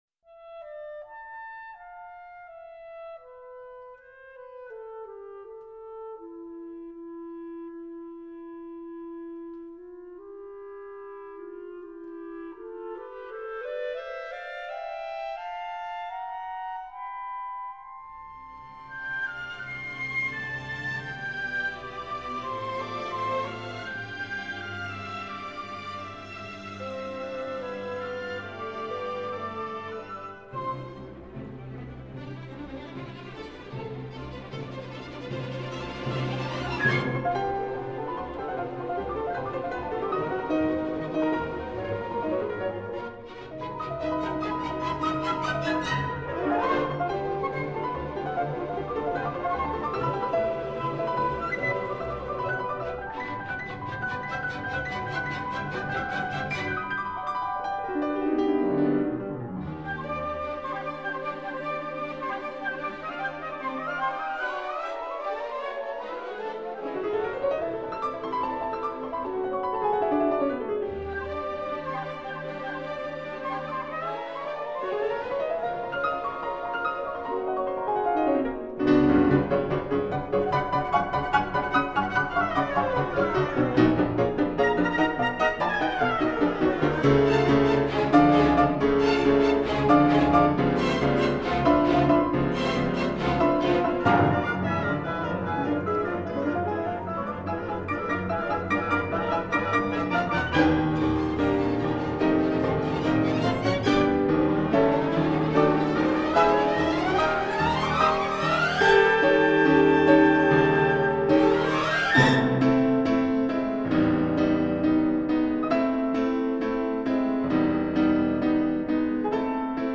Incontro con Viktoria Postnikova